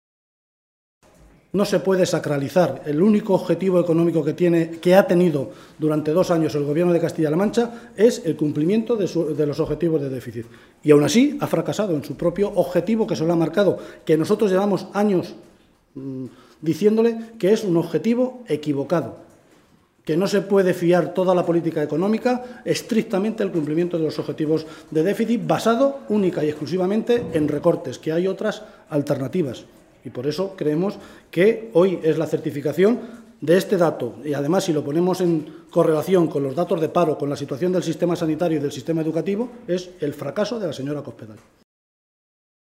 Guijarro se pronunciaba de esta manera esta tarde, en el Parlamento regional, en una comparecencia ante los medios de comunicación durante la celebración del Pleno de hoy, en la que valoraba los datos de déficit hechos públicos por el Ministerio de Hacienda, que señalan que nuestra región no ha cumplido el objetivo de déficit, al situarse por encima del 1,5 por ciento sobre el PIB exigido y dejarlo en el 1,53 por ciento.
Cortes de audio de la rueda de prensa